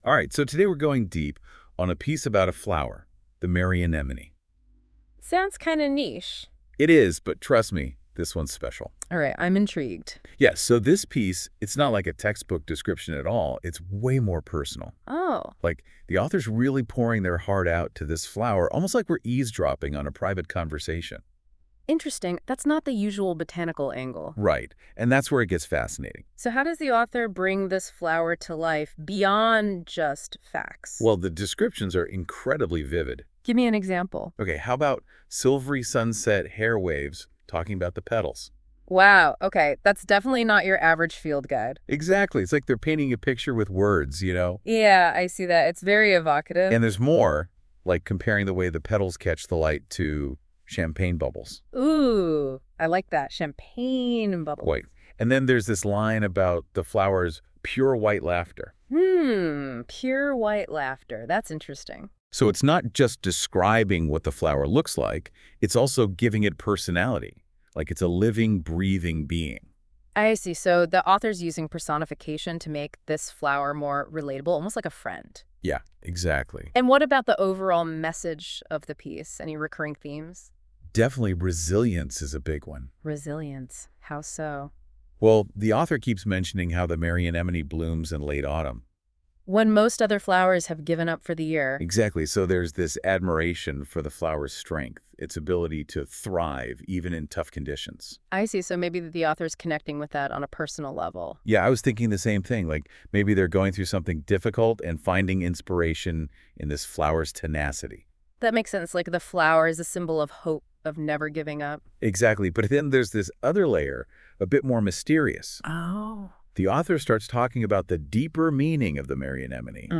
인공지능 팟캐스트 "심층 분석" WAV 파일 듣기 (영어):
AI Podcast - Mary Anemone The Autumn windflower.wav